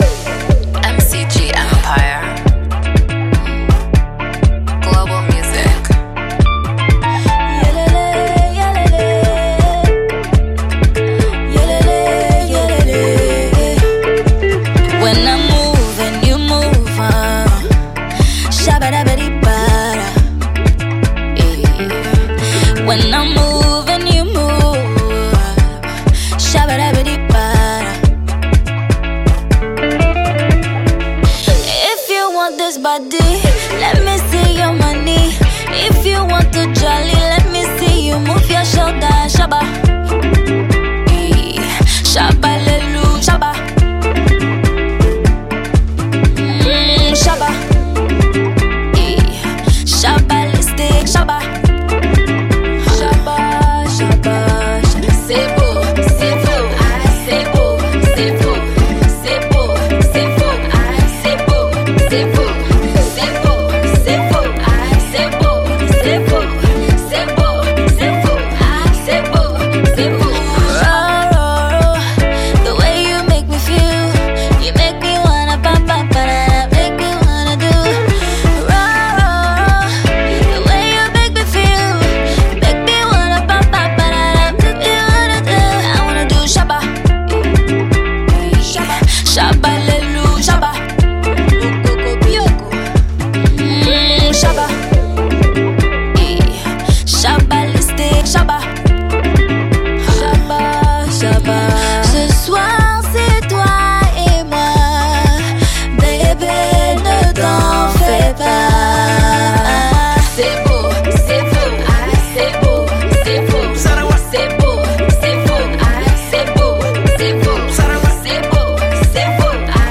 banging fire tune
guitar strings